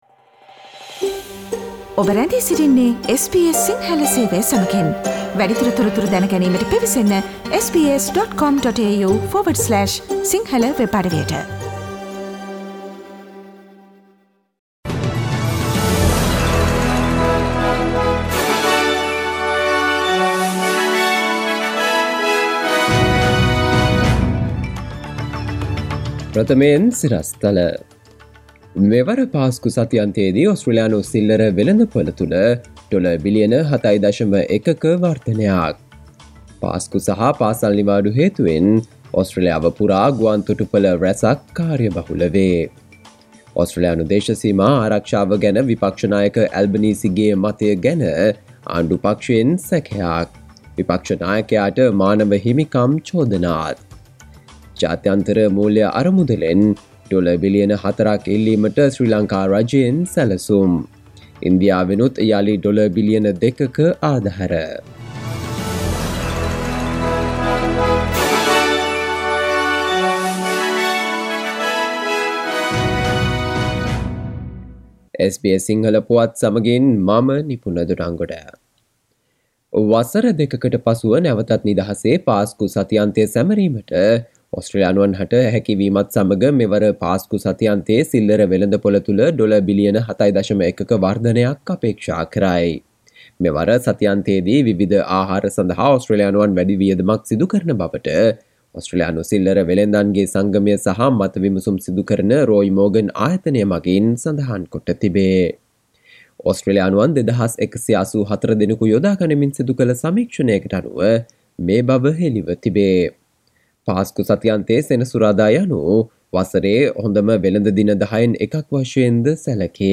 සවන්දෙන්න 2022 අප්‍රේල් 15 වන සිකුරාදා SBS සිංහල ගුවන්විදුලියේ ප්‍රවෘත්ති ප්‍රකාශයට...